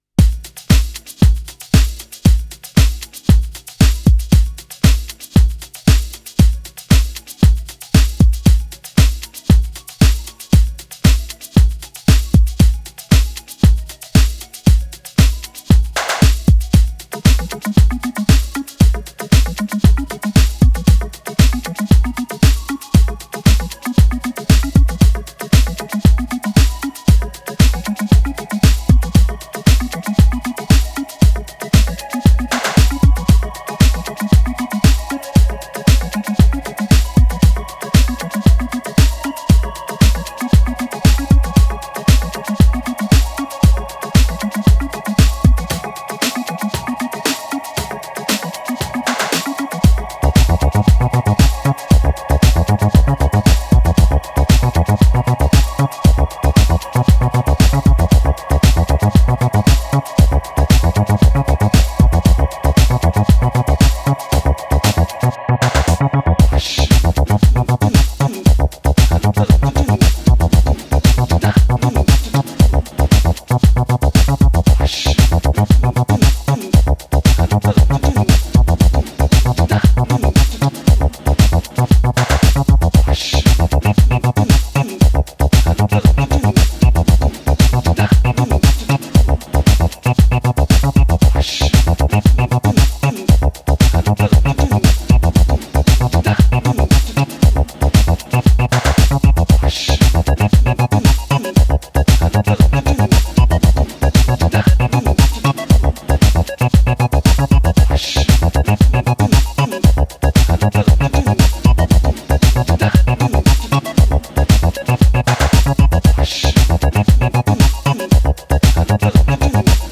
post-disco, new wave y el slow dark techno
piezas energéticas y con suaves altibajos